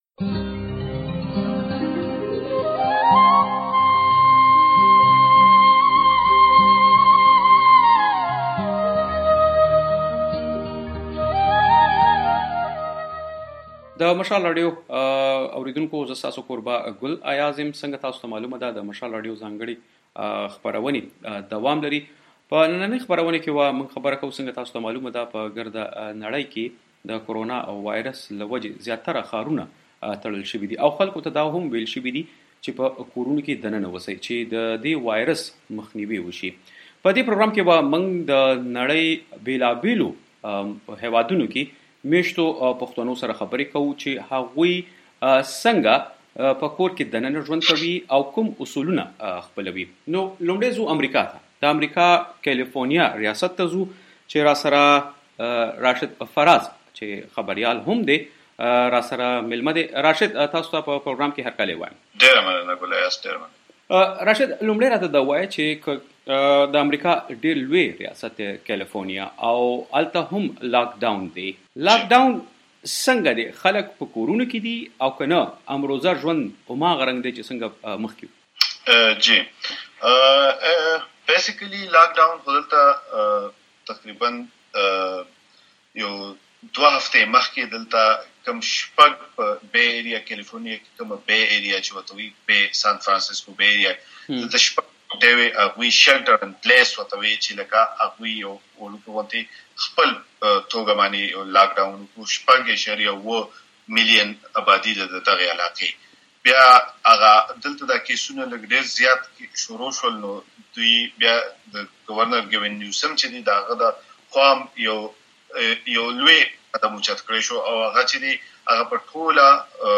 په امريکا،‌ اسټراليا،‌ جرمني او هالېنډ کې ميشتو ځینو پښتنو سره خبري کړي دي.‌